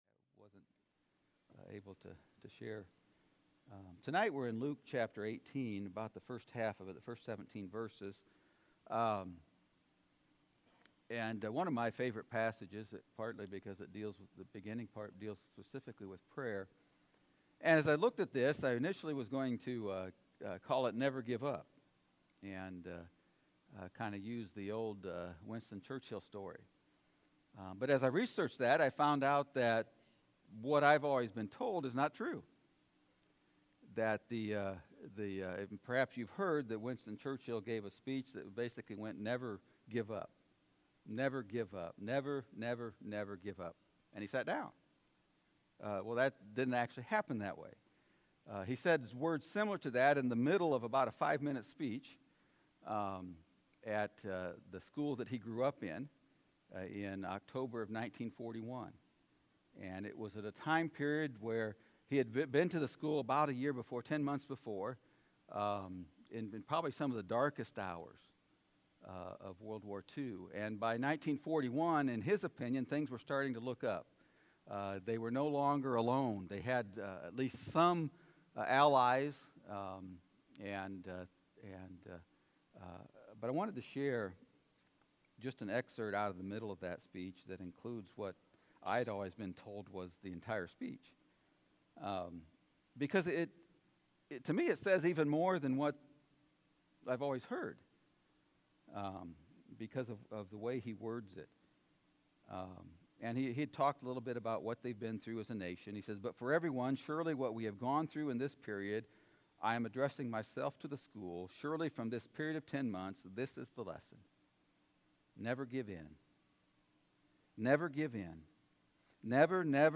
Sermon from the “Living Like Christ” series.